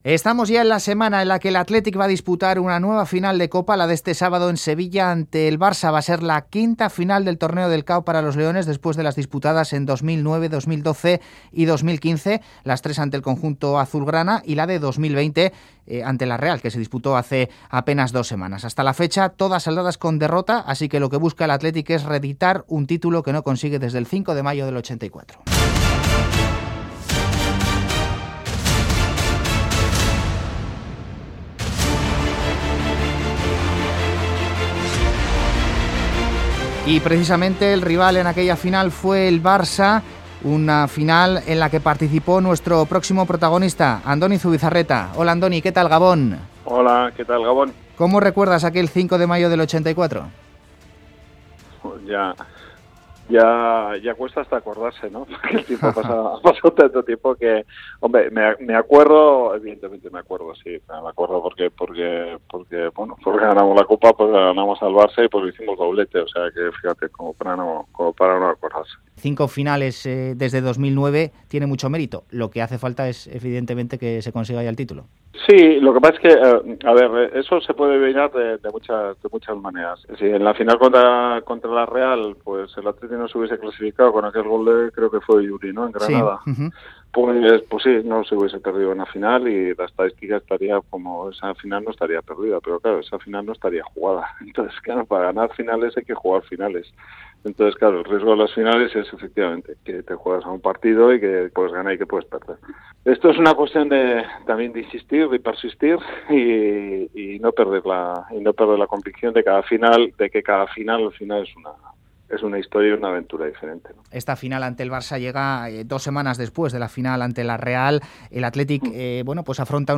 Audio: Ex jugador y ex director deportivo de Athletic y Barça, Andoni Zubizarreta analiza la final que el sábado va a enfrentar a ambos equipos en Sevilla